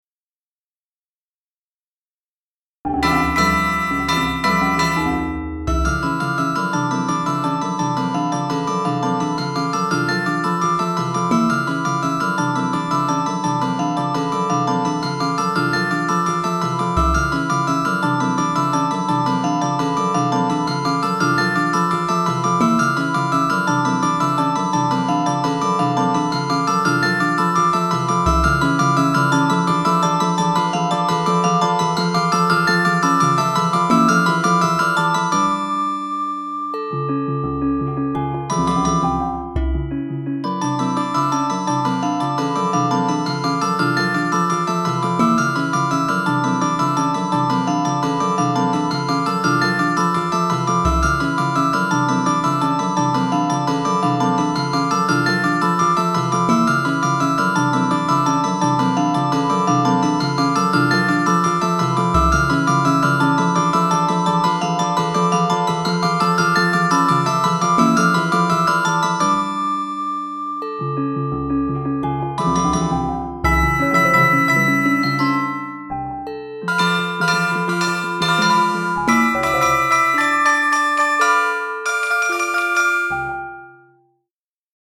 Klangschalen, Glockenspiel und diverse andere Instrumente sind zum Beispiel Begleiter bei Massagen, oder beim Joga, lassen sich aber auch in anderen Musikrichtungen verwenden.
is-meditation-1.mp3